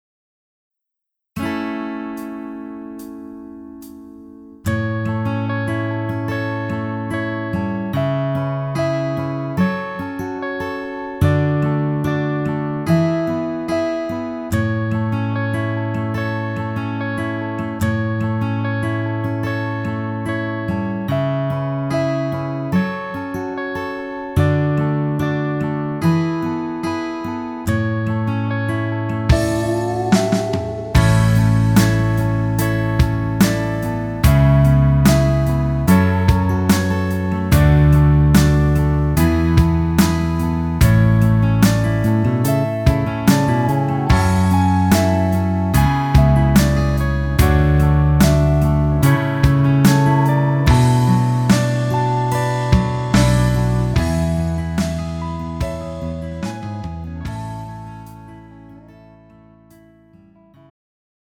음정 여자키 3:56
장르 가요 구분 Pro MR